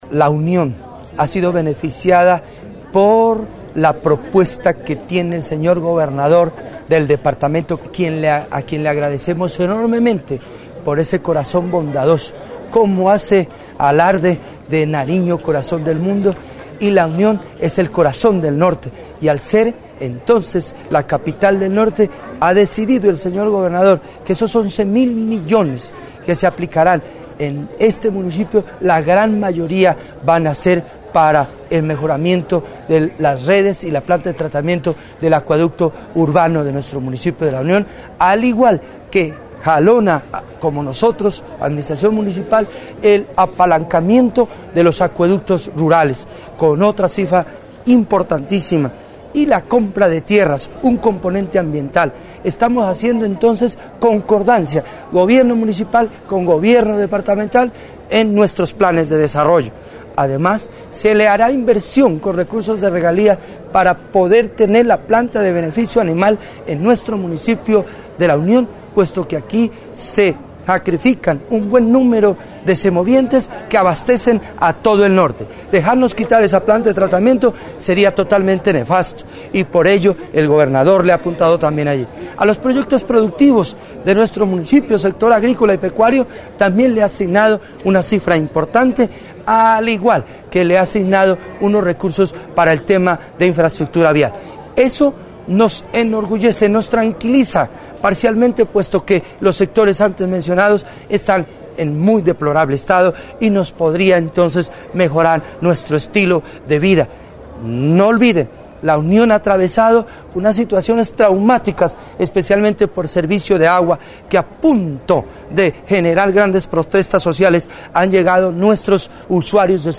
Entrevista al Alcalde del Municipio de La Unión, Floriberto Suárez
FLORIBERTO_SUREZ_-_ALCALDE_DE_LA_UNIN.mp3